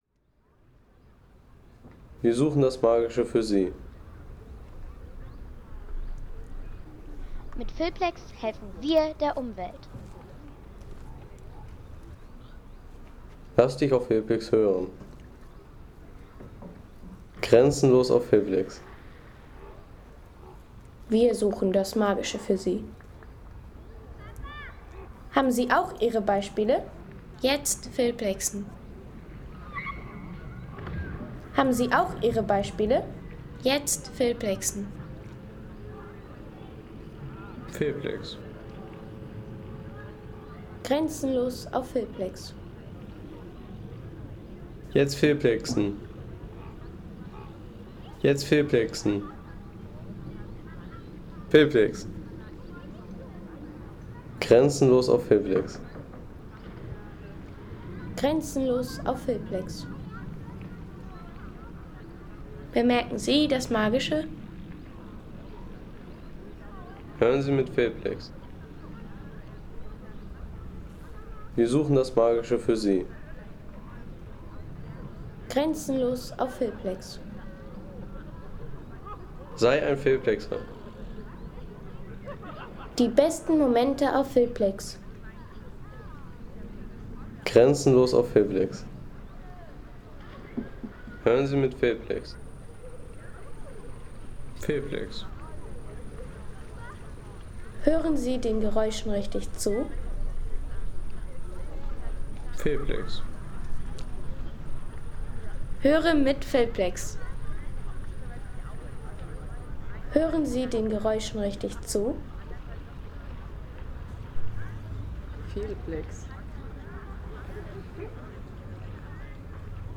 Tristacher See Soundeffekt für Film u. sommerliche Seeszenen
Tristacher See Soundeffekt | Lebendige Sommeratmosphäre am See
Lebendige Sommeratmosphäre vom Tristacher See bei Lienz mit Wasser, springenden Kindern, Enten und natürlicher Badesee-Stimmung.